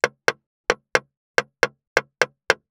466,切る,包丁,厨房,台所,野菜切る,咀嚼音,ナイフ,調理音,まな板の上,料理,
効果音